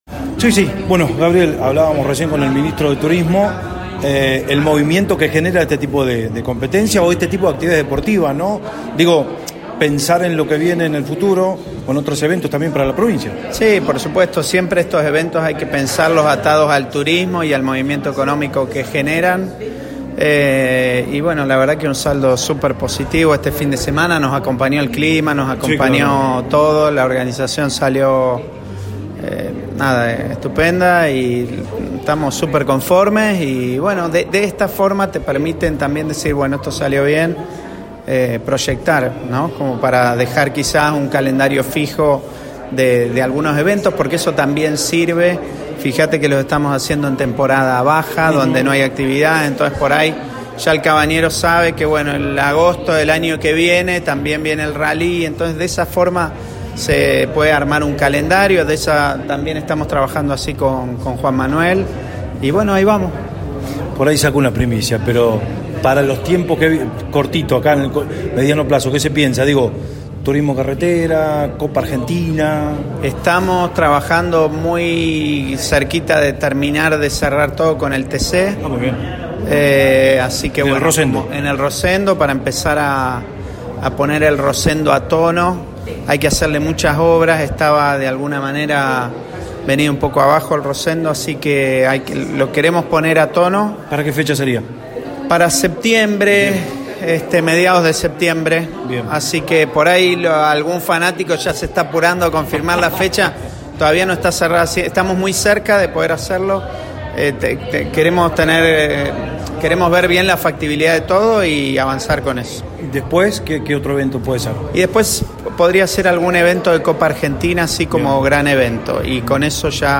Así lo entendió Gabriel Rivero, secretario de Deportes del Gobierno de la Provincia en diálogo con Show Deportivo, programa que se emite en FM Radio La Bomba.